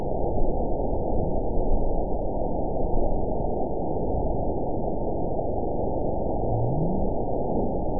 event 911378 date 02/24/22 time 15:21:27 GMT (3 years, 2 months ago) score 9.46 location TSS-AB04 detected by nrw target species NRW annotations +NRW Spectrogram: Frequency (kHz) vs. Time (s) audio not available .wav